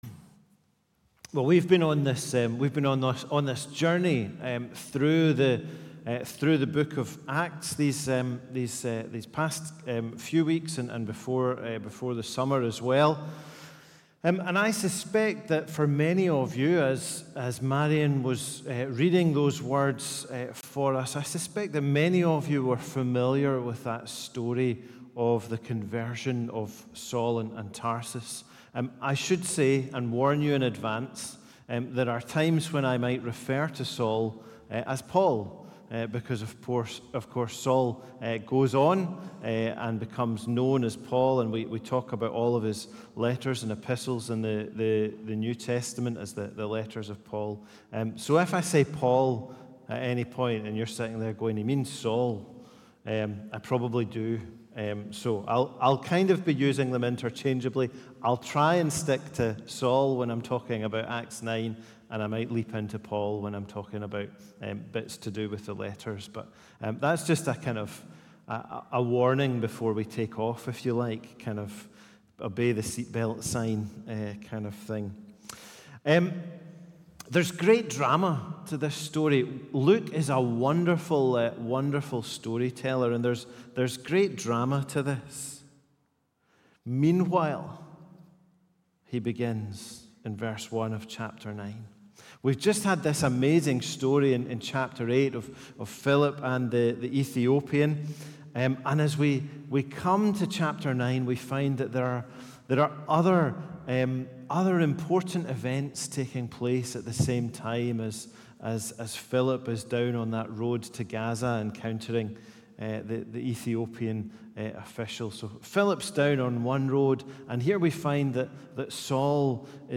A Sermon from the series "Acts ."